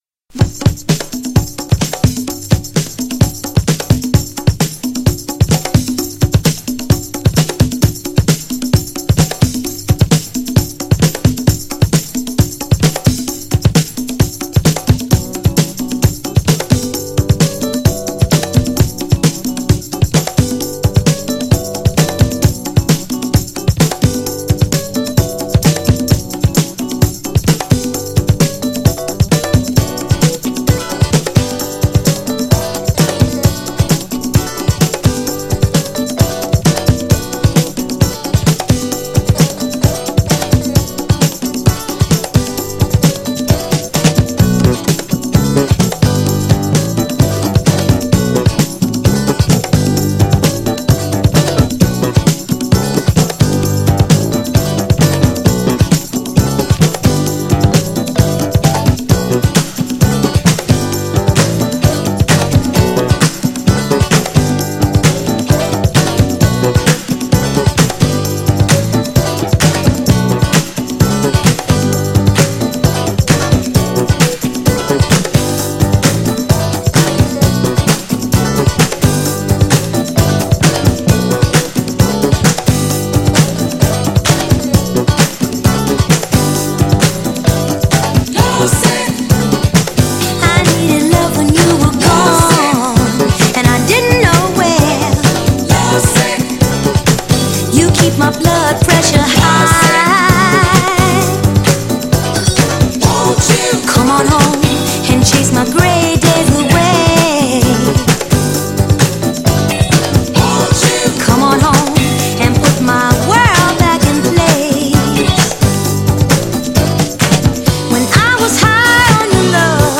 ドラマティックなB面どちらも良い!!
GENRE Dance Classic
BPM 131〜135BPM
HAPPY系サウンド
アップリフティング
メロディアス